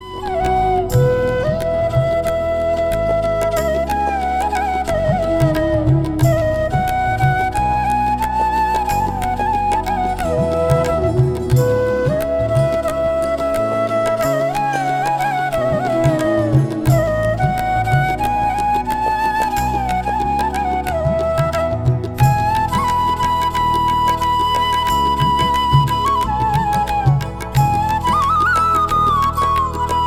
Instrumental ringtone